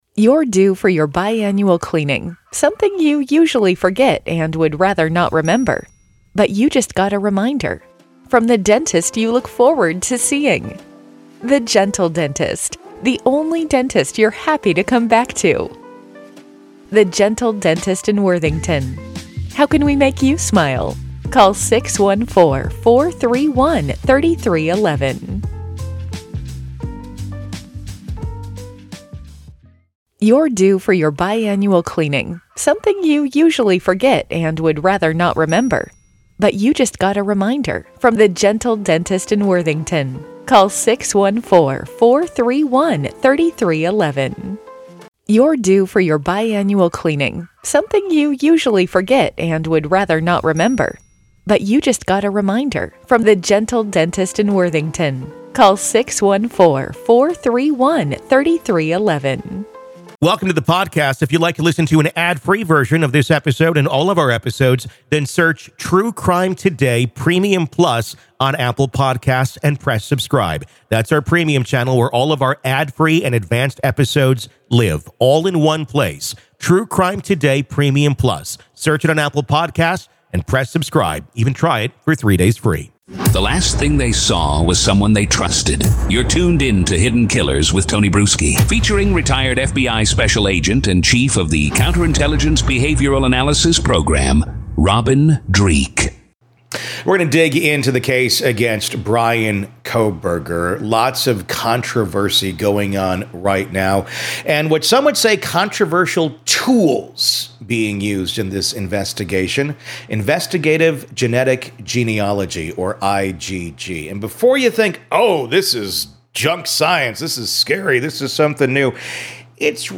FBI Unscripted | Real Agents On Real Crime